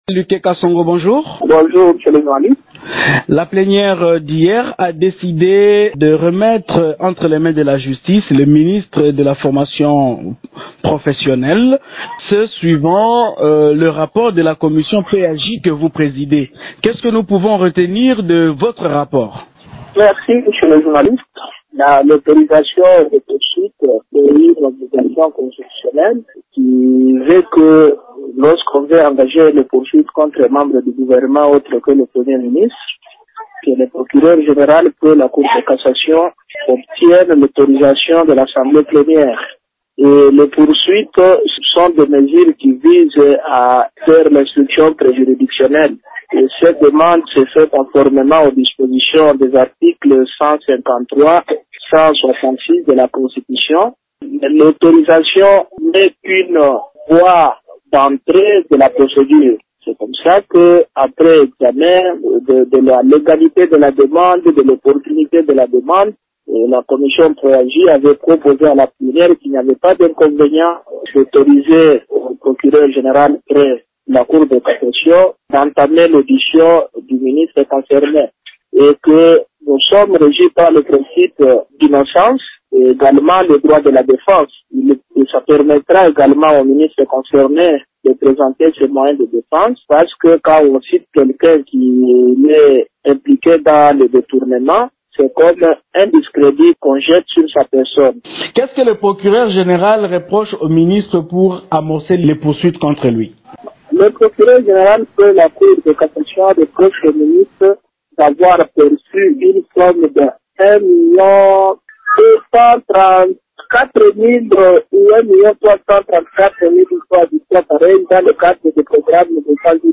Vous pouvez écouter ici Lucain Kasongo président de la commission Politique administrative et juridique de l’assemblée nationale